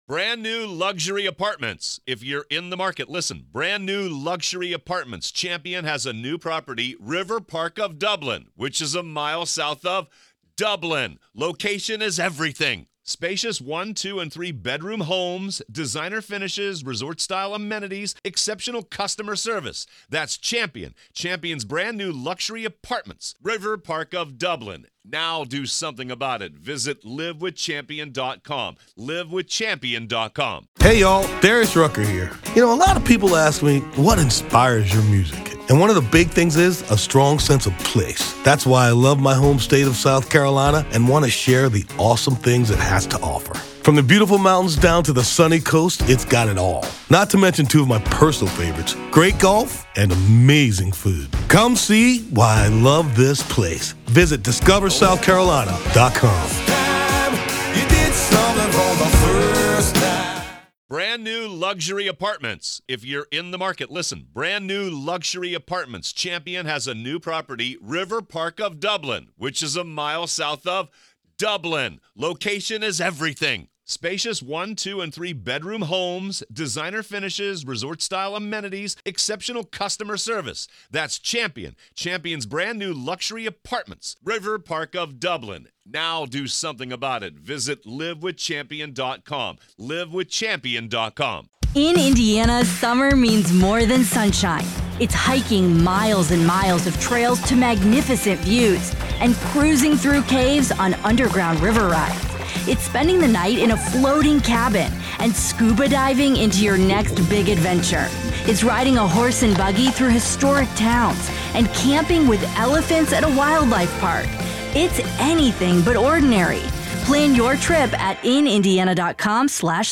They dissect whether these brags could be seen as incriminating evidence, potentially swaying the court's perception of Kohberger's character and actions. Analyzing from legal and psychological viewpoints, they delve into how such statements could influence jurors and the overall trajectory of the trial. This intriguing discussion prompts listeners to consider the fine line between casual conversation and evidence in a criminal trial.